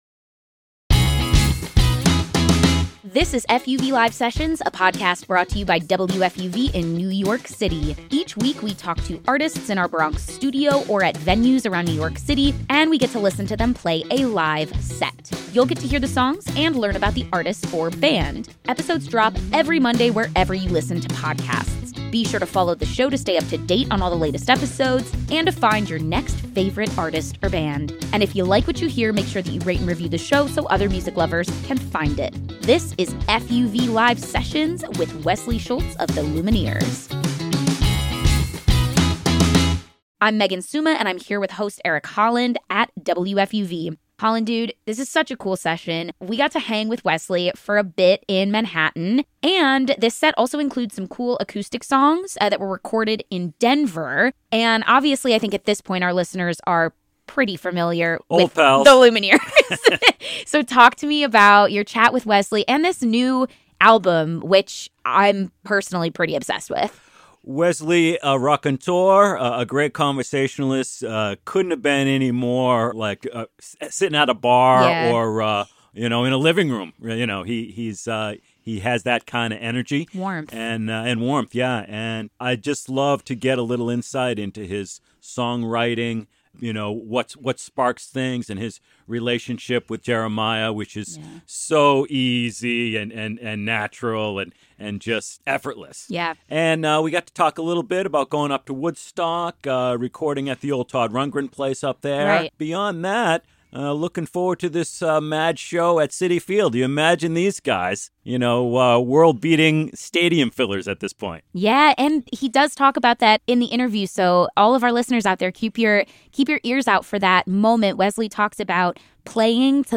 live songs from a special duo performance